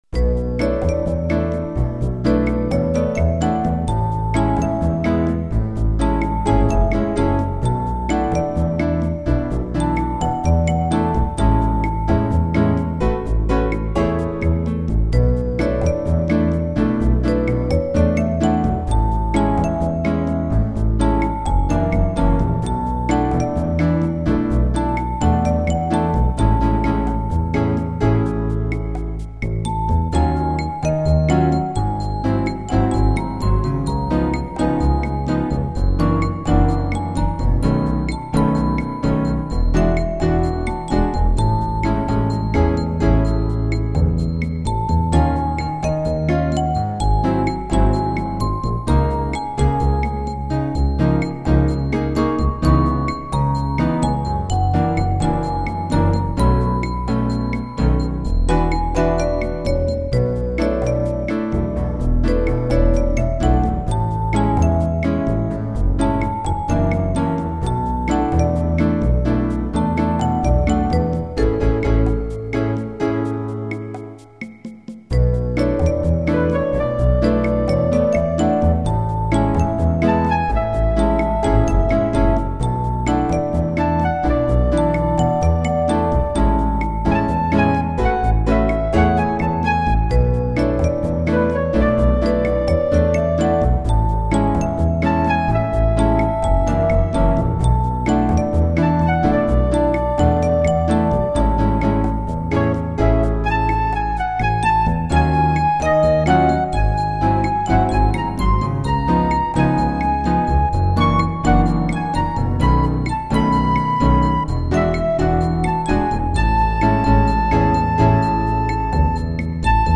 Roland MT-32 and Sound Canvas Enhanced version.